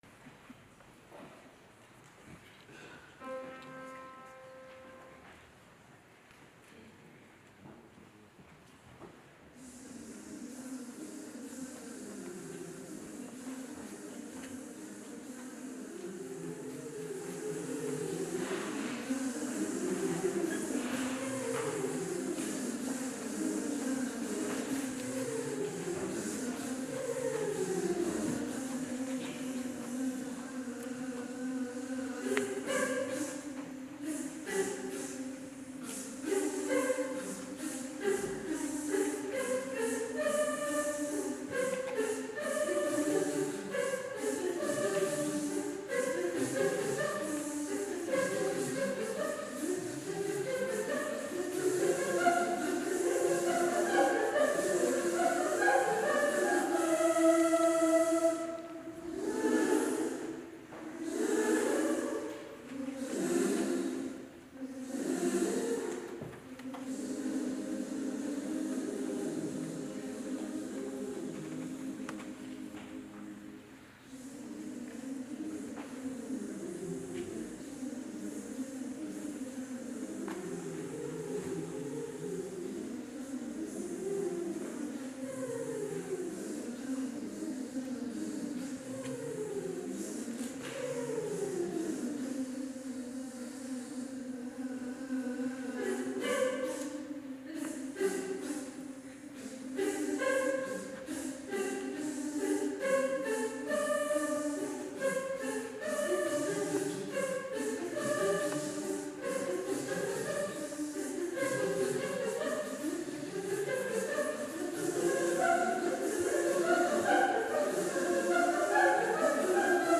Full Performance